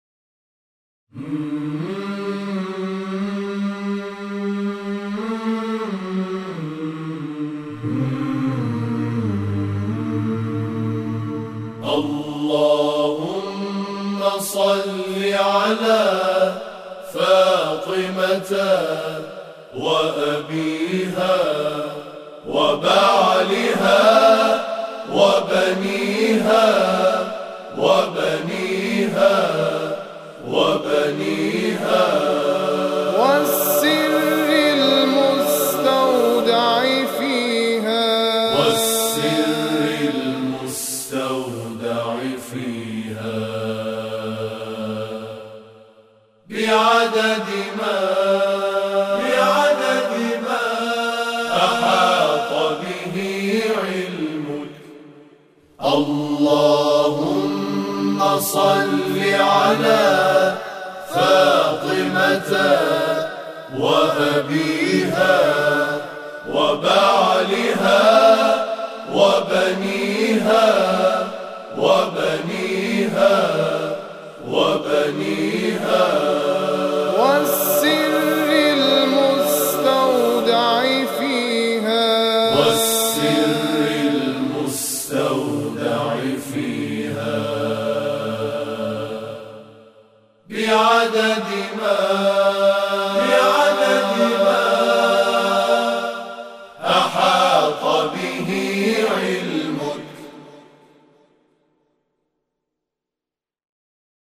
به مناسبت میلاد حضرت فاطمه زهرا (س)، نما‌آوای صلوات خاصه آن حضرت، اثری از گروه تواشیح الغدیر تهران ارائه می‌شود.